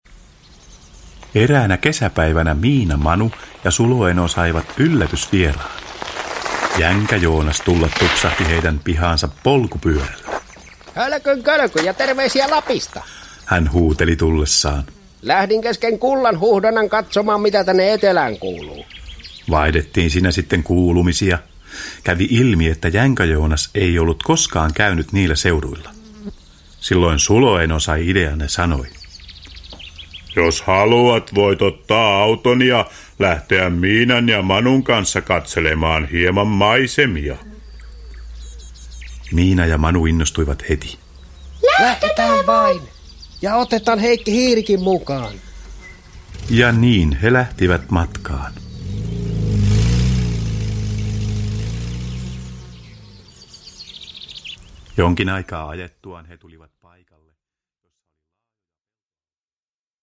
Miina ja Manu Myllyn tarina – Ljudbok – Laddas ner